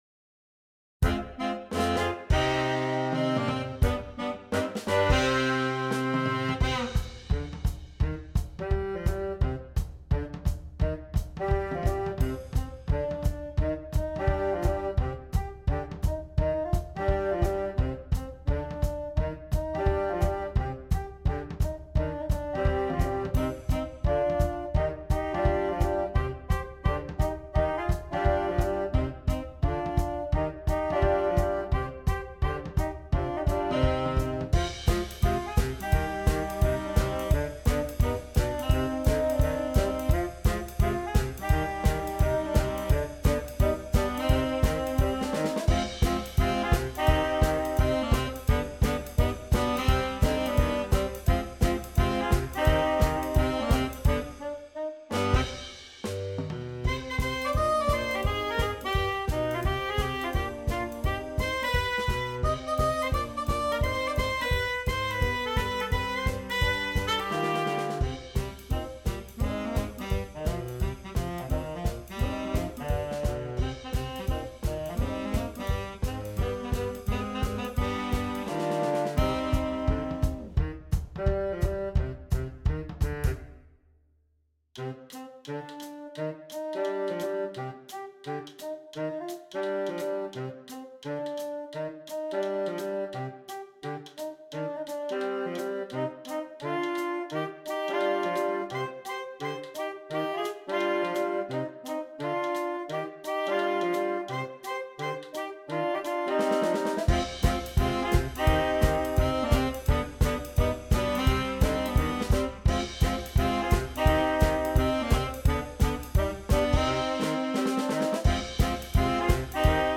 Saxophone Quartet (AATB) Optional Drums
jump swing version of the traditional Hanukkah song